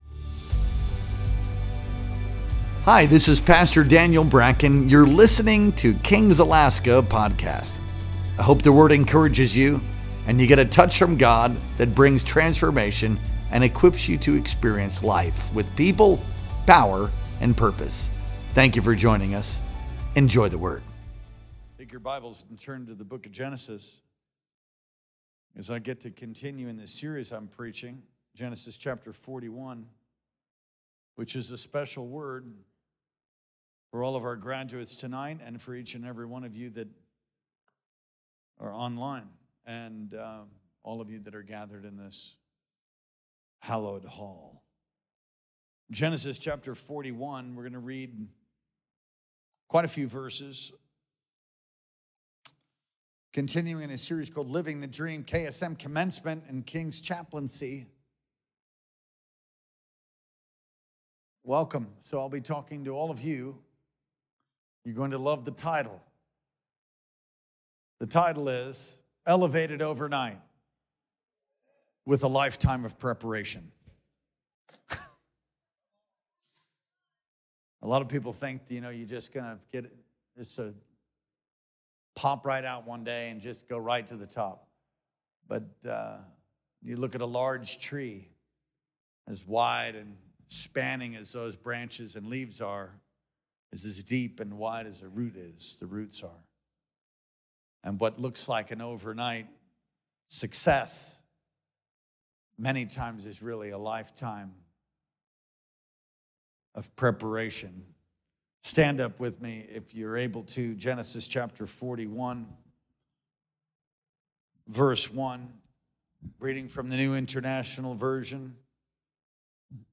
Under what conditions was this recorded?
Our Wednesday Night Worship Experience streamed live on May 14th, 2025.